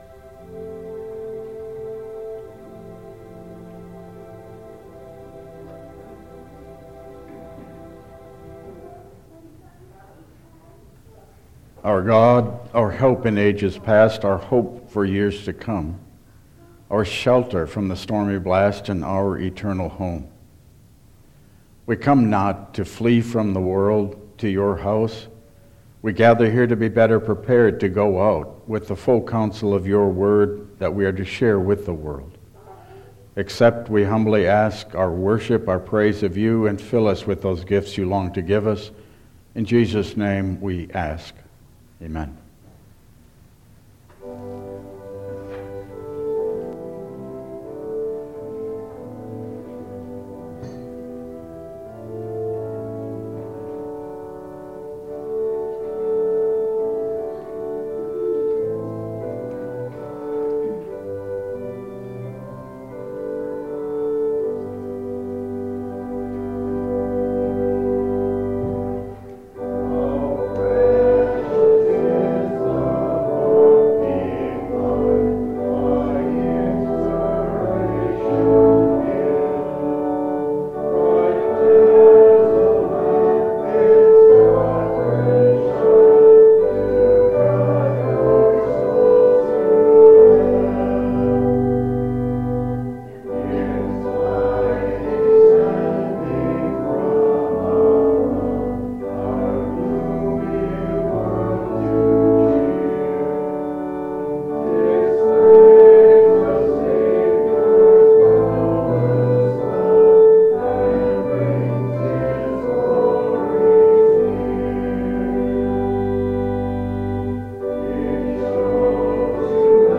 Passage: Jeremiah 26:1-6 Service Type: Regular Service